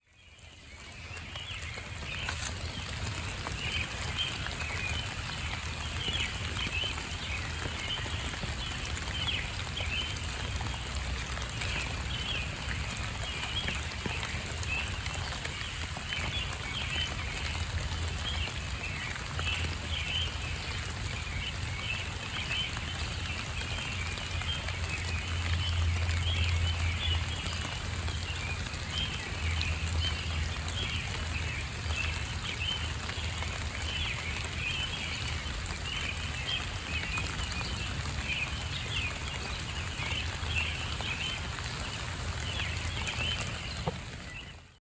This morning, the full-throated pre-dawn bird chorus mingled with the sound of rainfall as Pixie and I walked through the dark wet woods and back to the house.
morningsongrain.wav